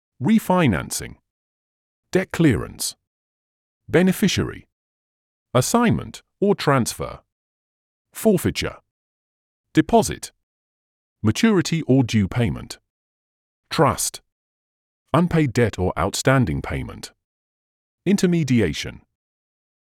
Cliquez sur les icônes fille et garçon pour écouter la prononciation.